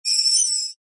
Metal-Scrape-HighPitched